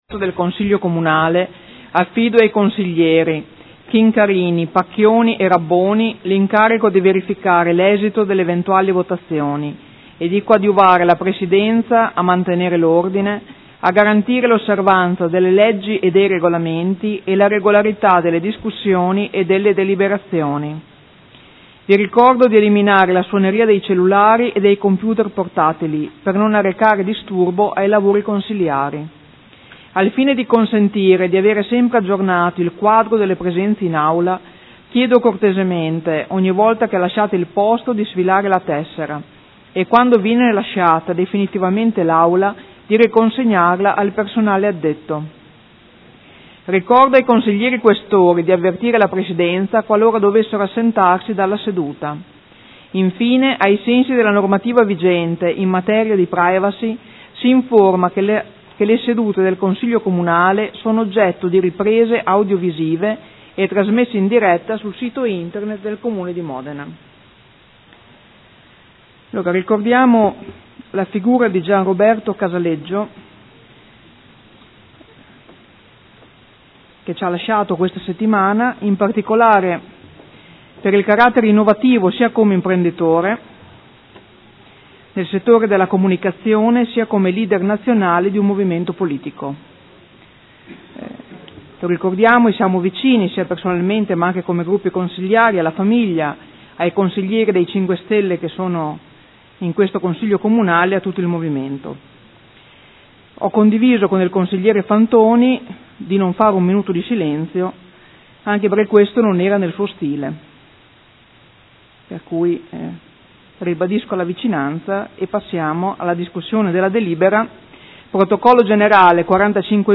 Presidentessa — Sito Audio Consiglio Comunale
Seduta del 14/04/2015 Apre i lavori del Consiglio. Ricorda la figura di Casaleggio.